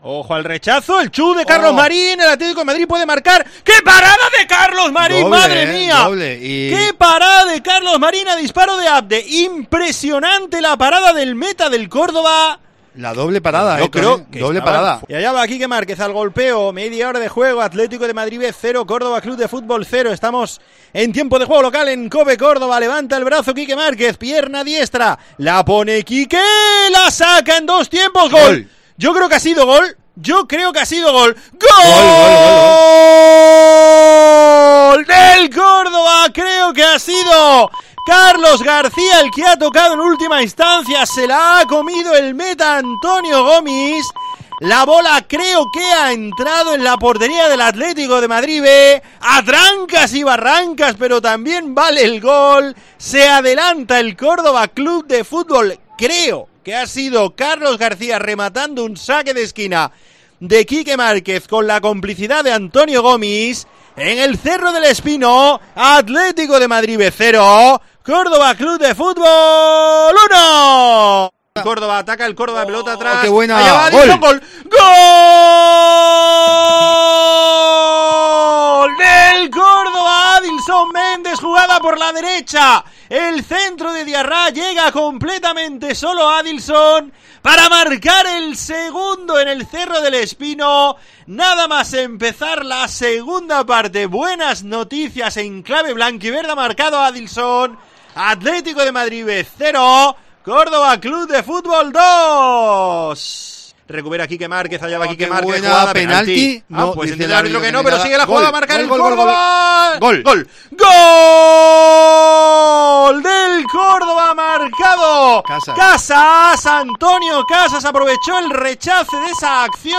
Resumen sonoro del Atlético de Madrid B 1- Córdoba CF 6